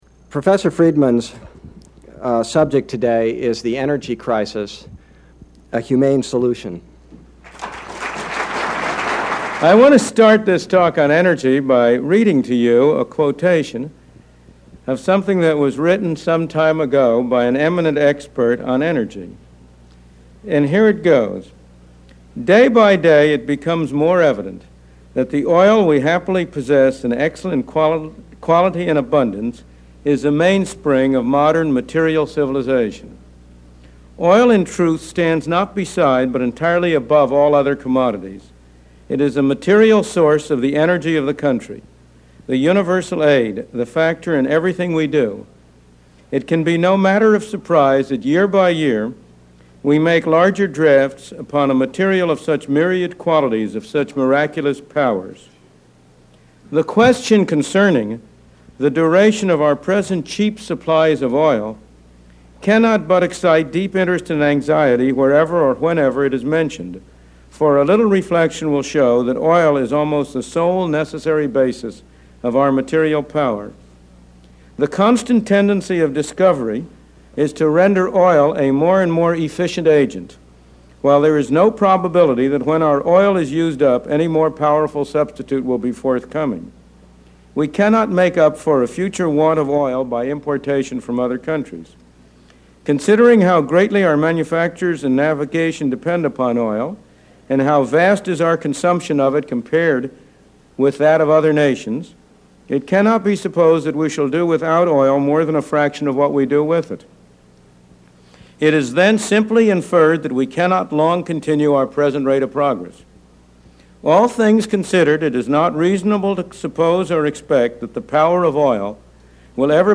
Episode 9 - Milton Friedman Speaks - The Energy Crisis